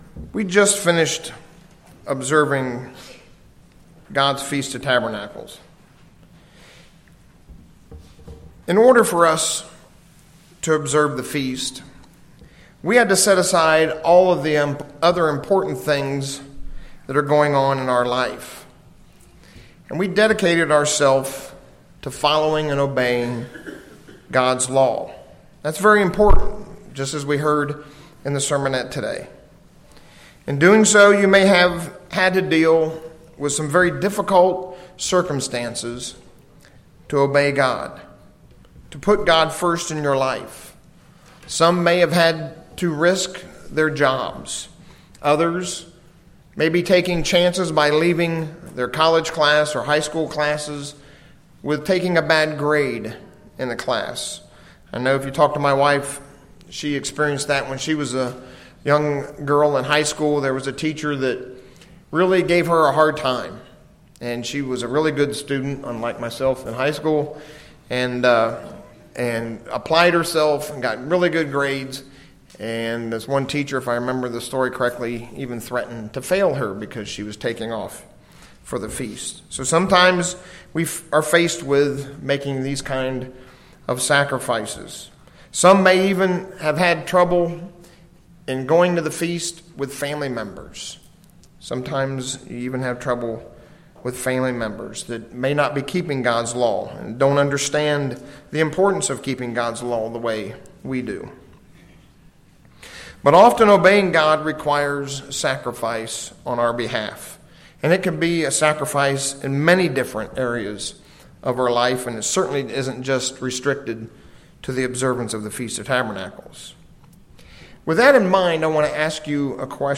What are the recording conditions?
Given in Ft. Wayne, IN